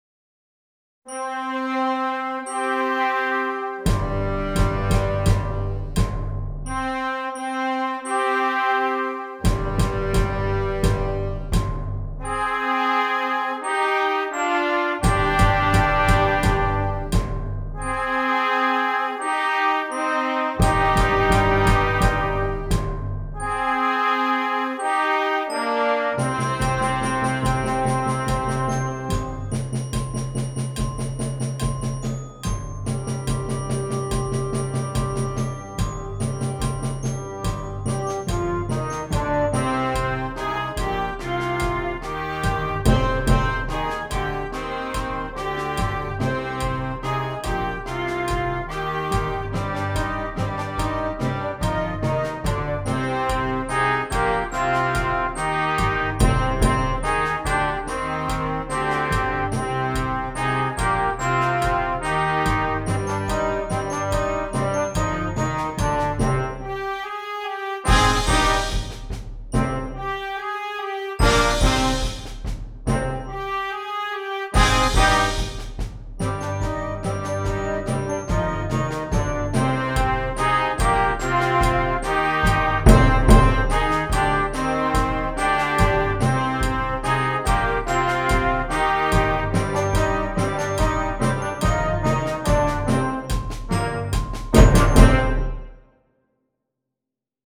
Concert Band
beginning band piece